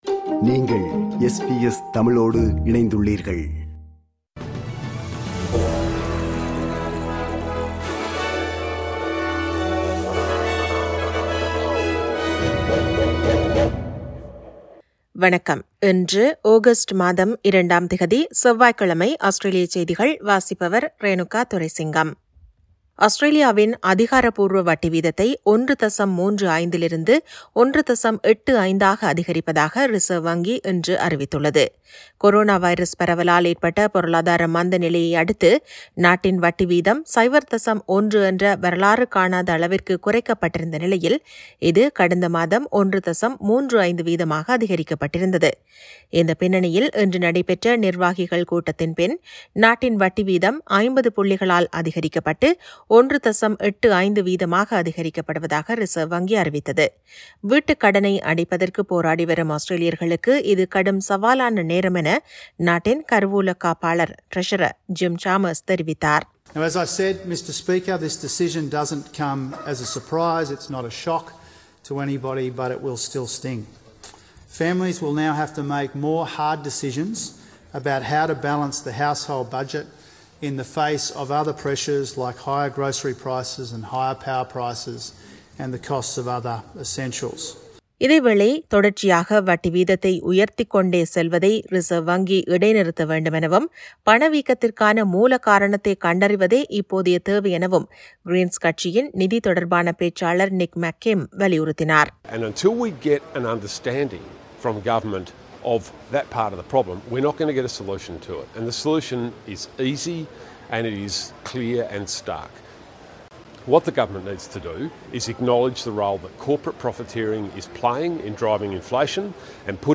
Australian news bulletin for Tuesday 02 Aug 2022.